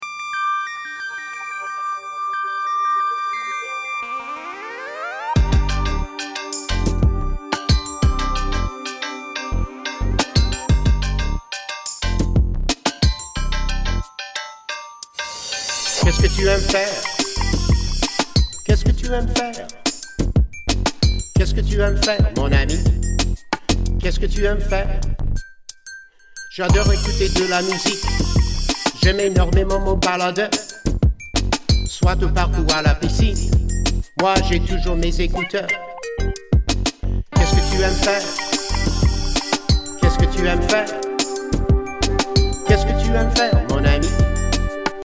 French Language Raps
Ten original raps on CD.